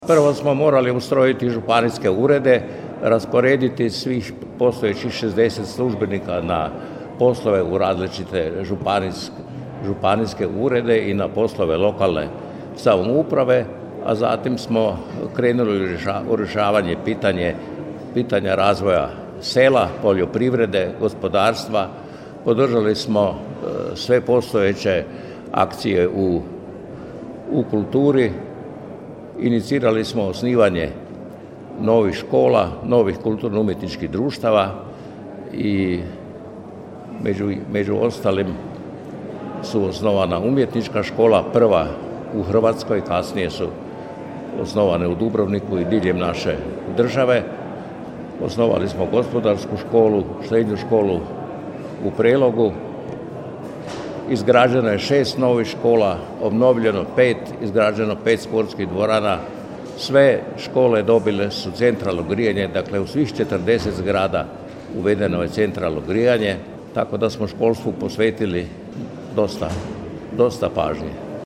Međimurska županija, 12. sjednica svečani dio u povodu 30. godišnjice konstituiranja prvog saziva Skupštine Međimurske županije, 13.4.2023.
Prvi je međimurski župan podsjetio na prve korake tadašnje vlasti: